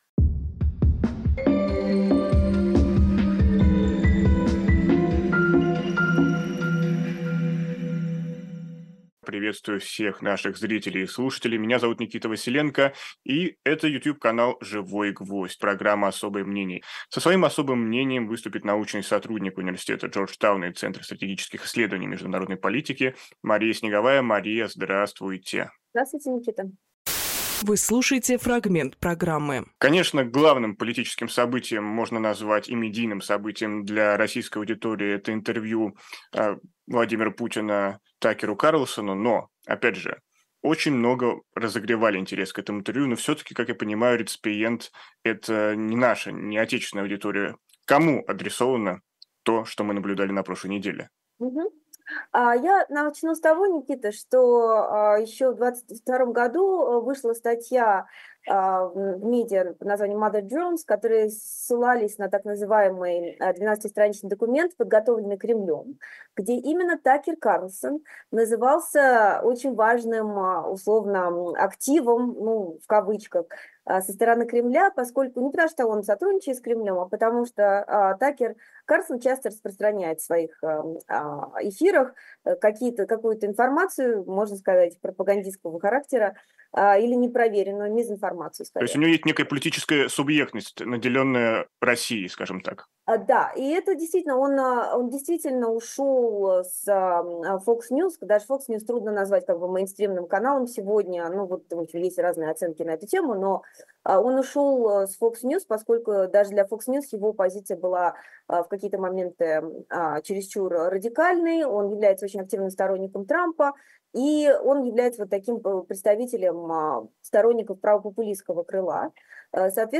Фрагмент эфира от 12.02.24